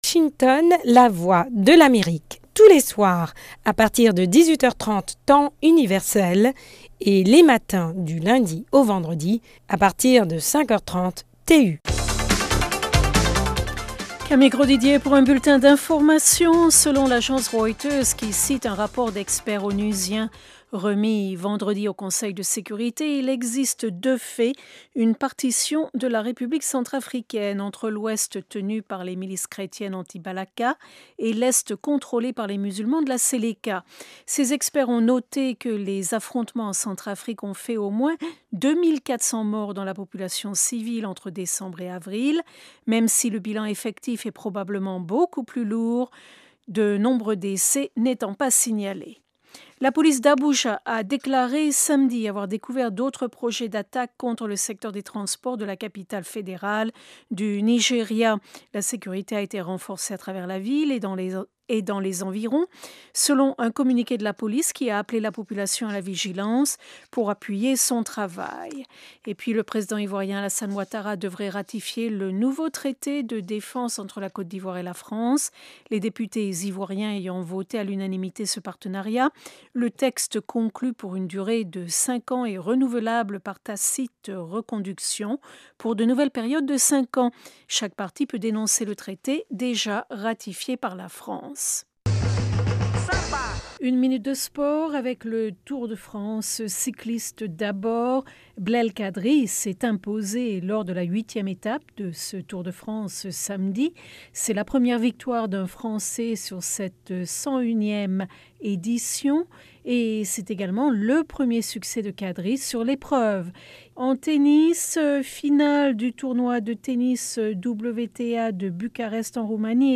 Le Magazine au Féminin de la Voix de l’Amérique a tendu le micro à Pramila Patten, Vice-présidente du CEDAW de l'Onu, le Comité pour l'Elimination de la Discrimination à l'Egard des Femmes, pour parler de la situation des femmes et des filles en Mauritanie, en Inde et en Syrie.